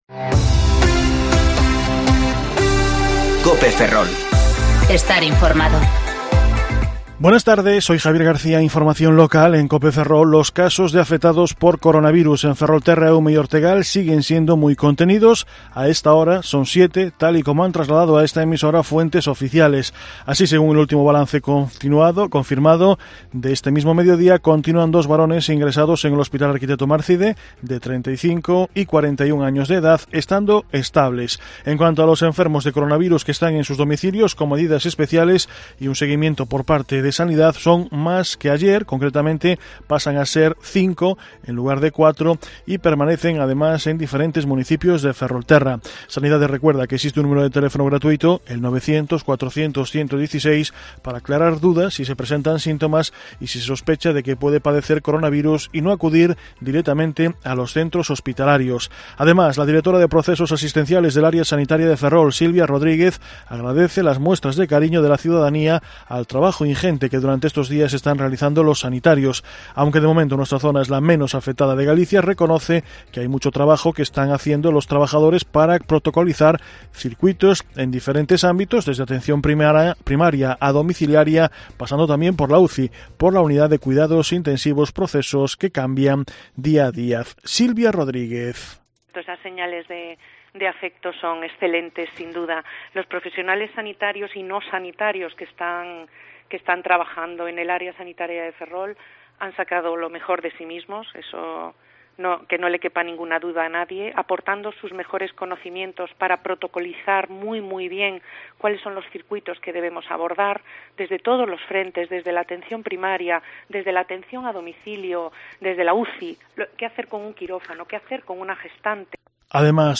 Informativo Mediodía COPE Ferrol - 16/03/2020 (De 14,20 a 14,30 horas)